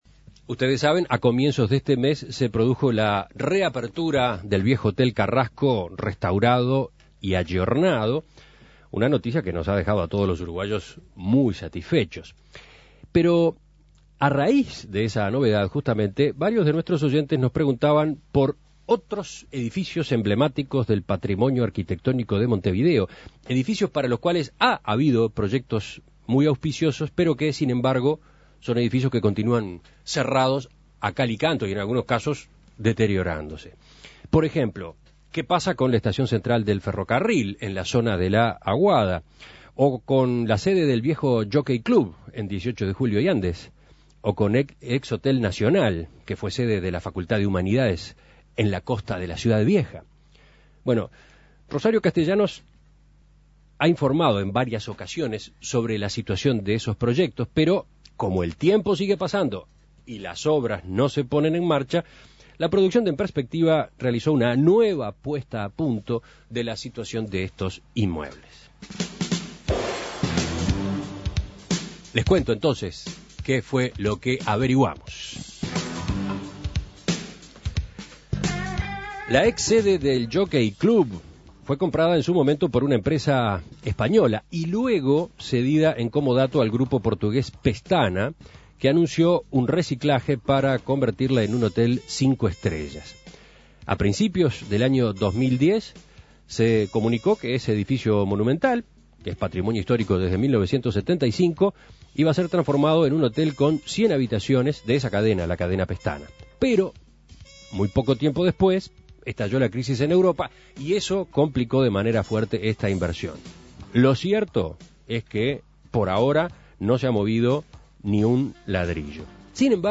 Informe de En Perspectiva.